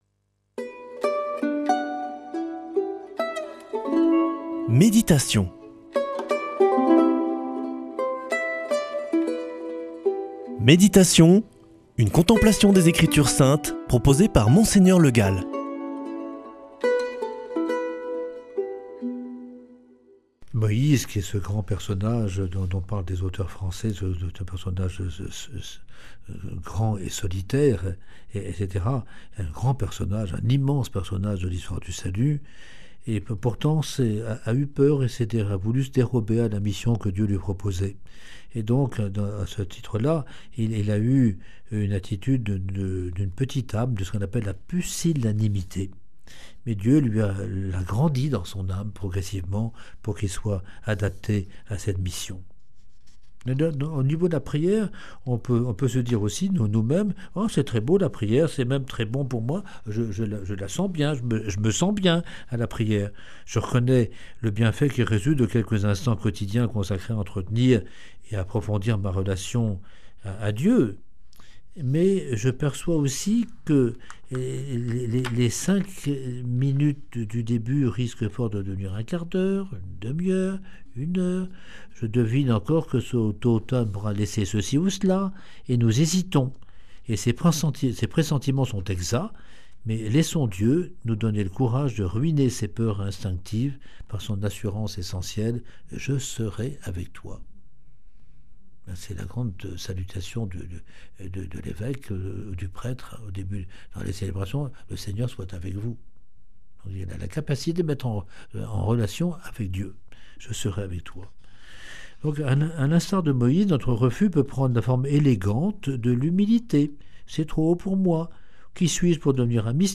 lundi 23 juin 2025 Méditation avec Monseigneur Le Gall Durée 7 min
Présentateur